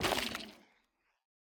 Minecraft Version Minecraft Version latest Latest Release | Latest Snapshot latest / assets / minecraft / sounds / block / sculk / break13.ogg Compare With Compare With Latest Release | Latest Snapshot